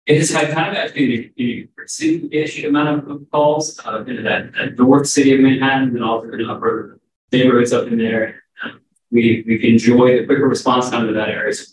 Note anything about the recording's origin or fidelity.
Riley County Commissioners met in Randolph Thursday evening for the third of four quarterly meetings in the county.